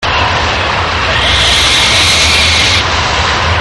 Águila Poma Milano Blanco
Black-and-chestnut Eagle White-tailed Kite